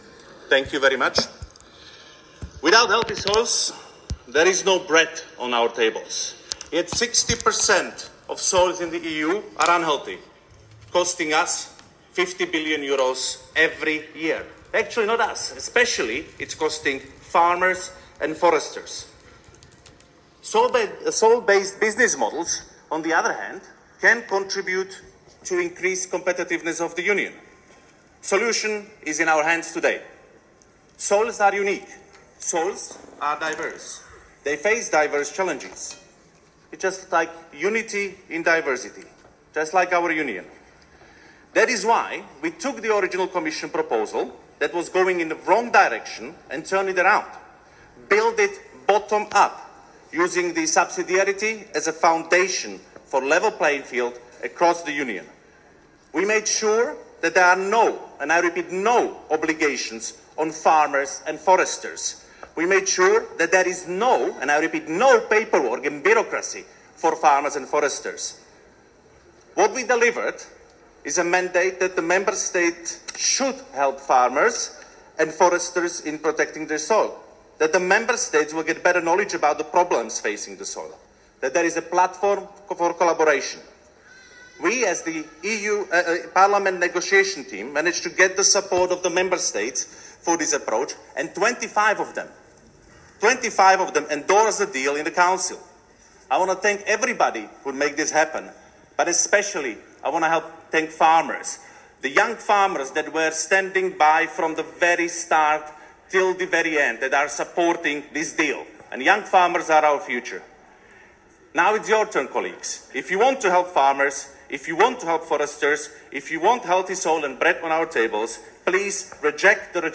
L’intervento del vicepresidente del Parlamento europeo, Martin Hojsík